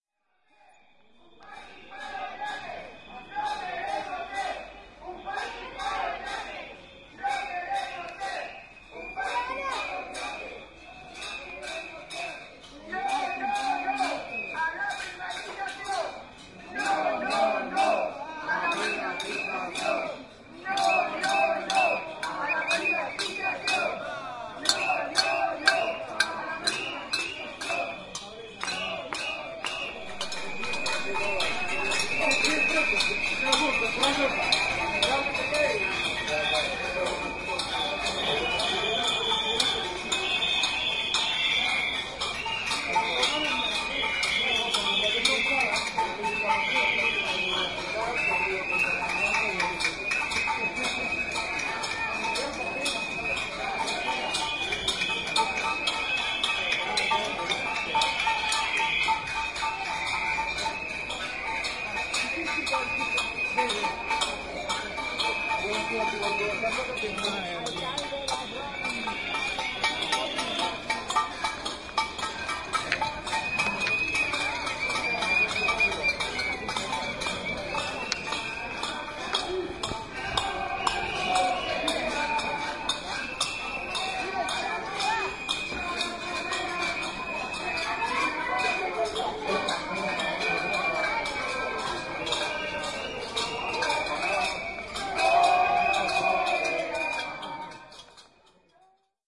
描述：我们听到了反对该区私有化的示威声音。人们拿着哨子上街，用木勺子在锅上刺，也喊着反对私有化。"
声道立体声